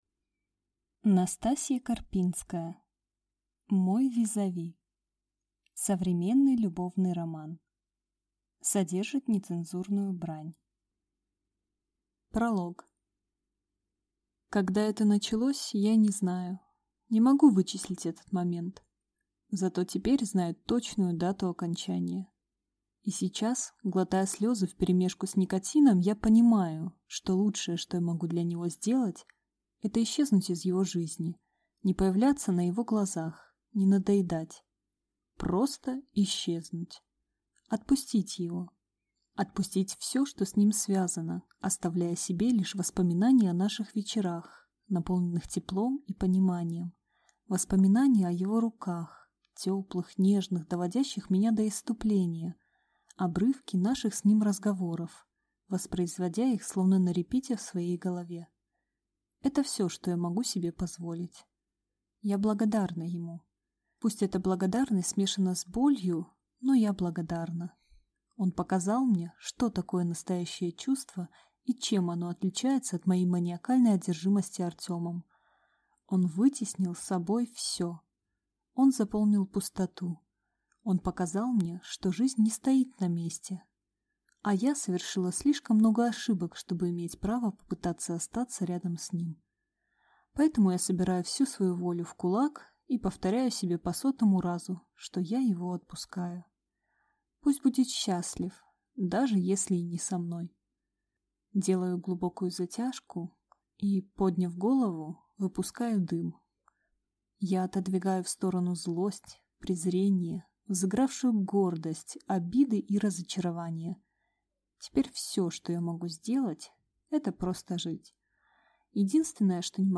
Аудиокнига Мой визави | Библиотека аудиокниг
Прослушать и бесплатно скачать фрагмент аудиокниги